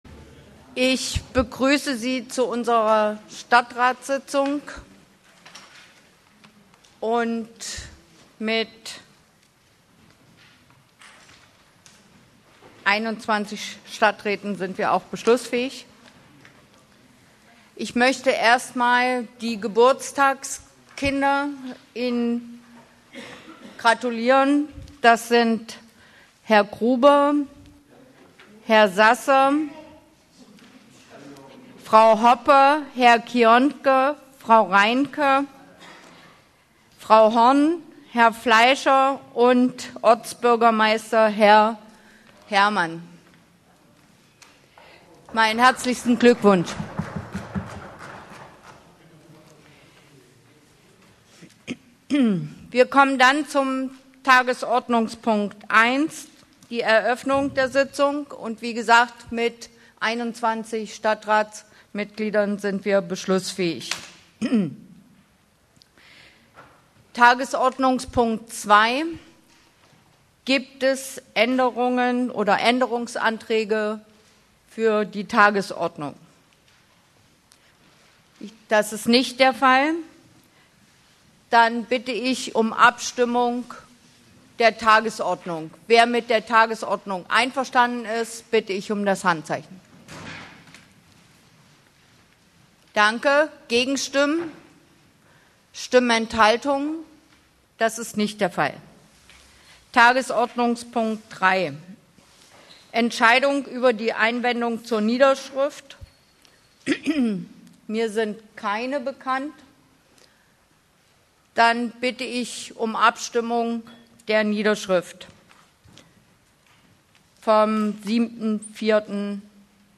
Der Ascherslebener Stadtrat hat wieder im Bestehornhaus getagt. Diesmal ging es zum Beispiel um den Jahresabschluss der Seeland-GmbH, an der die Stadt beteiligt ist, um eine Änderung bei den Abwassergebühren, um die Entschädigung der Feuerwehrleute und es wurden die Weichen gestellt für die Sanierung einer Brücke in Mehringen sowie für eine umfassende Sanierung der Sanitäranlagen in der Kita „Pünktchen“. radio hbw hat den öffentlichen Teil der Sitzung aufgezeichnet.